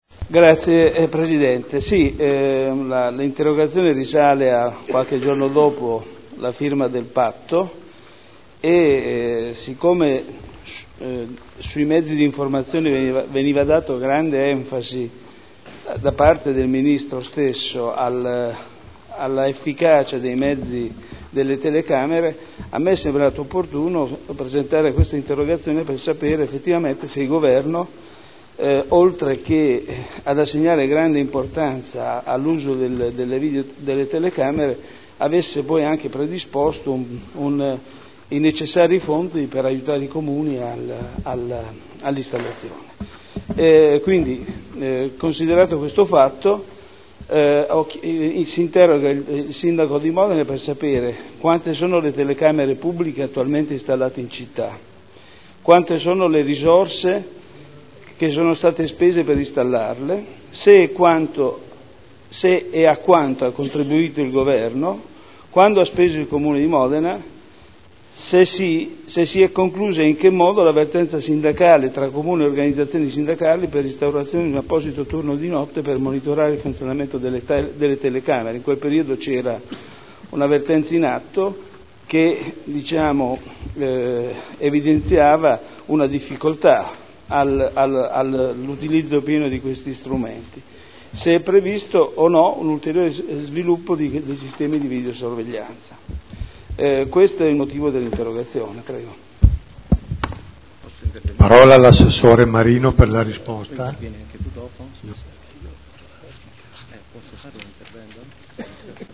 Seduta del 17 ottobre Interrogazione del consigliere Andreana (P.D.) avente per oggetto: “Dopo la firma del “Patto per Modena Sicura” quali progetti per la sicurezza si intende sviluppare e quale ruolo si intende assegnare all’utilizzo degli strumenti di video sorveglianza?”